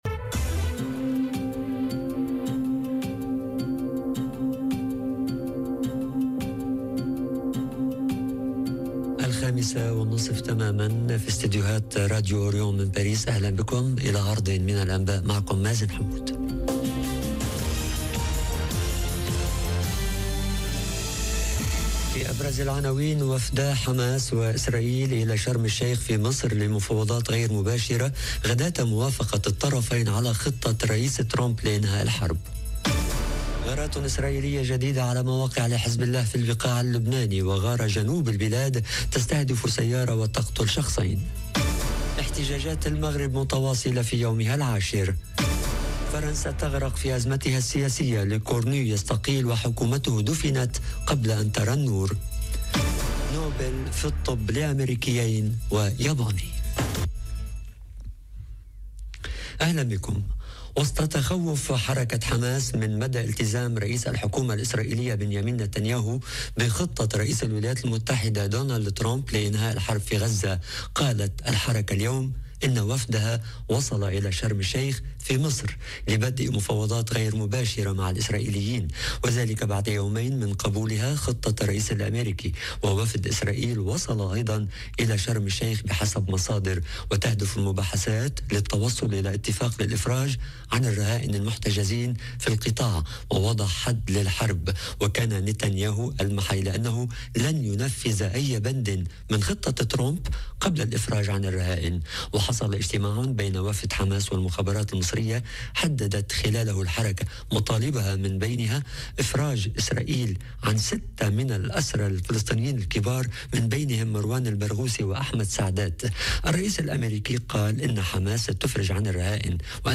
نشرة أخبار المساء: بنيامين نتانياهو يؤكّد إنه لم يوافق على قيام دولة فلسطينية خلال محادثاته مع ترامب... - Radio ORIENT، إذاعة الشرق من باريس